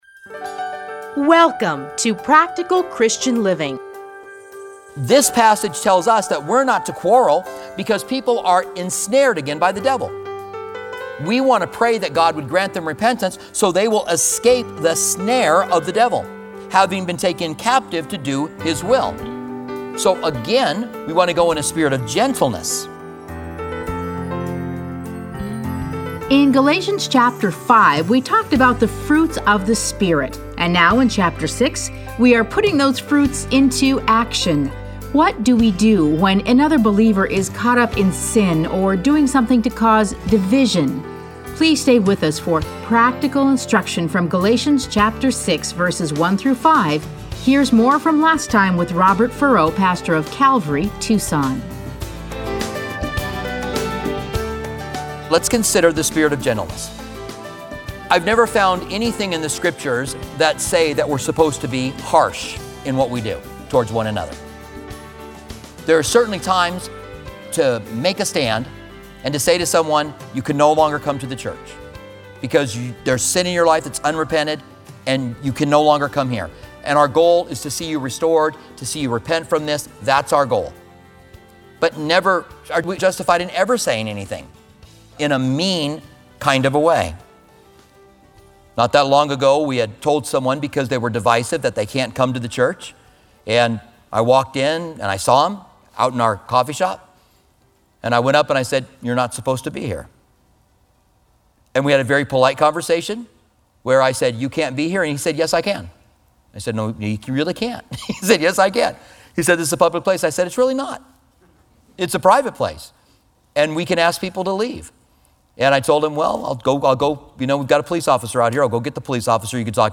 Listen to a teaching from Galatians 6:1-5.